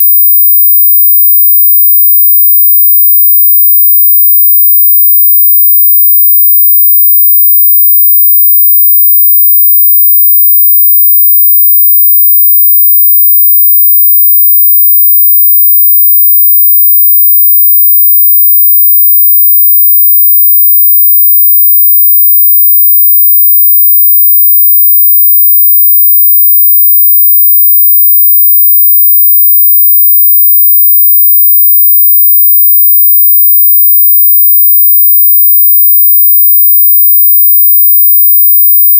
24歳以下までが聞こえる音と言われています。